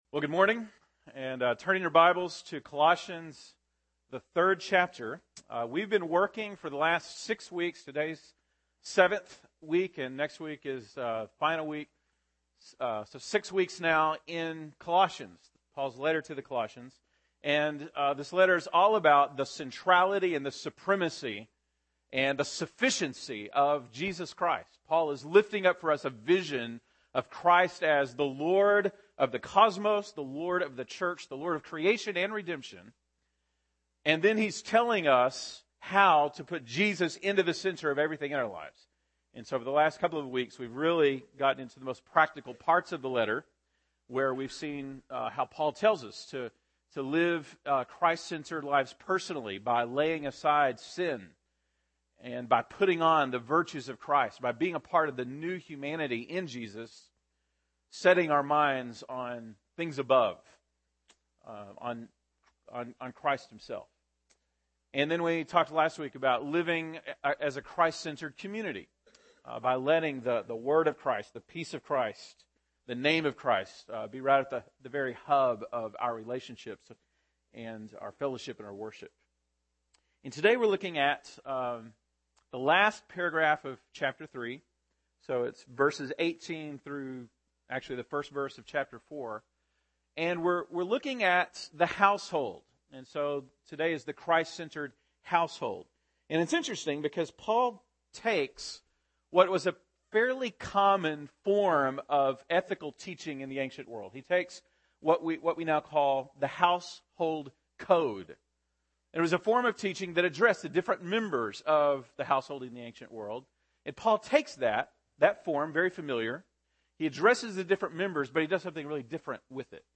March 10, 2013 (Sunday Morning)